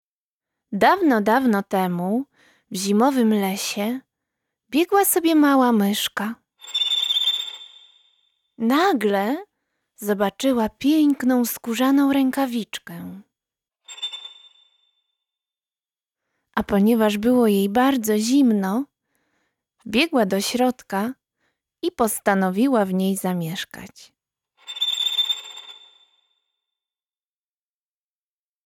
Muzyczna Rękawiczka to słuchowisko stworzone przez grupę Muzyka Łączy; było wielokrotnie wystawiane, między innymi na festiwalu Sputnik, w Kinie Iluzjon, Domu Kultury Kadr, Centrum Łowicka.
01. Narrator